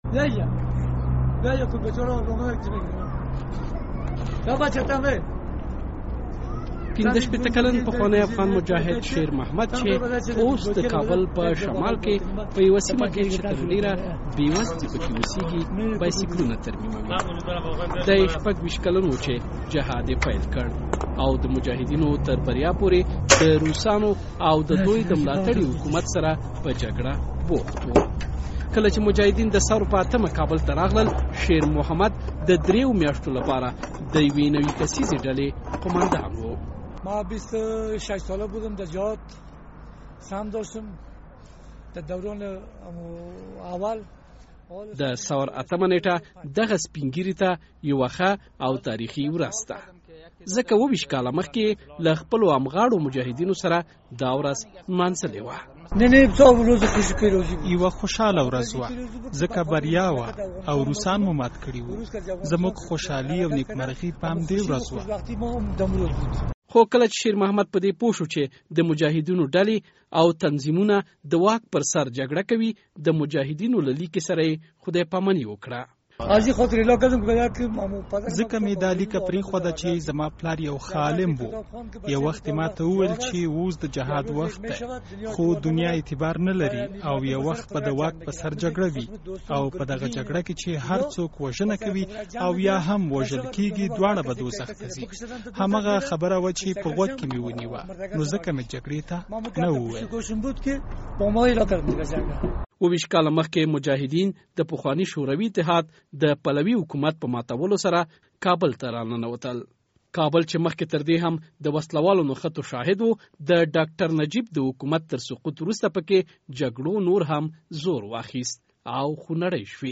غږیز راپور